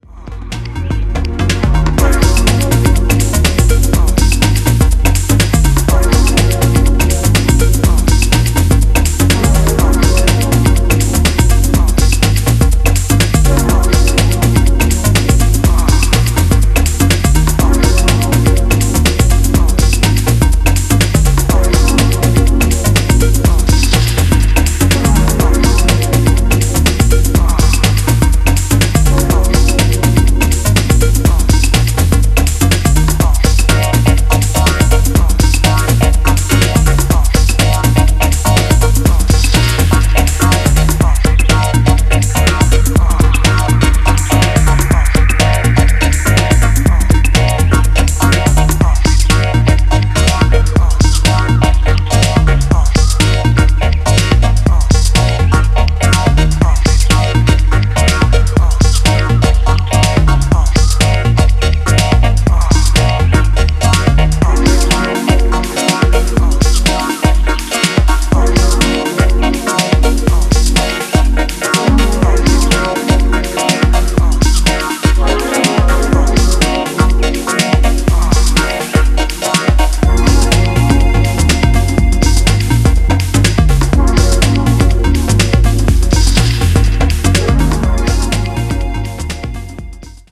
ジャンル(スタイル) DEEP HOUSE / TECHNO